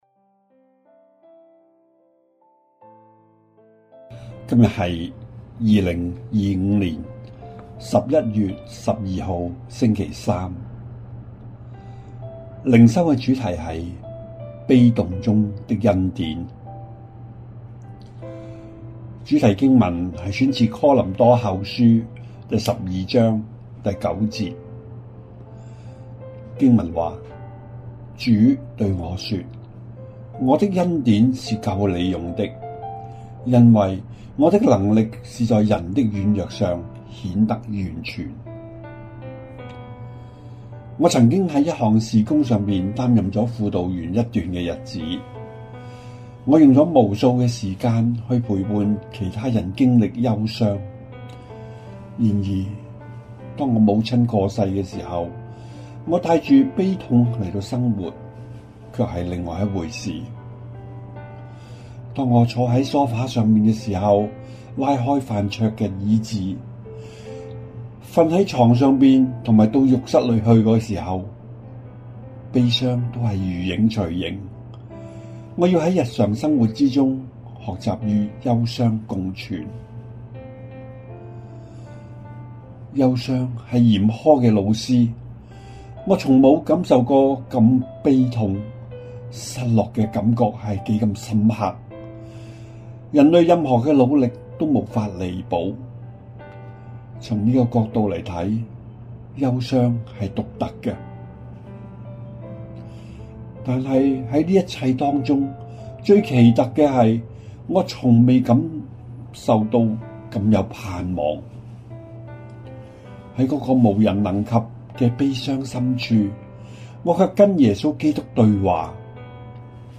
循道衞理聯合教會香港堂 · 錄音佈道組 Methodist Outreach Programme